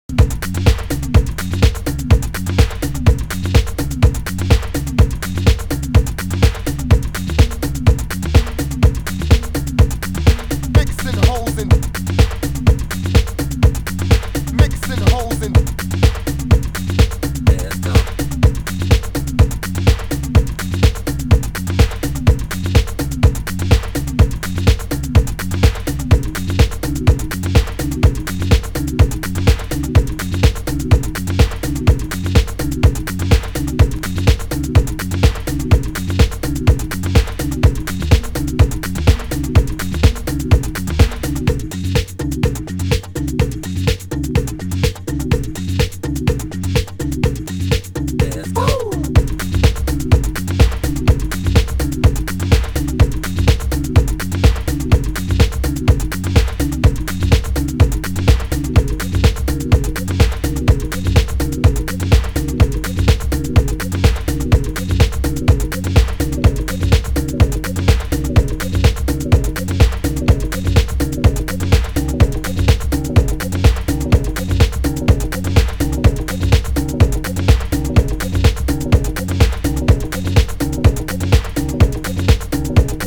盤質：軽いスレ、少しチリパチノイズ有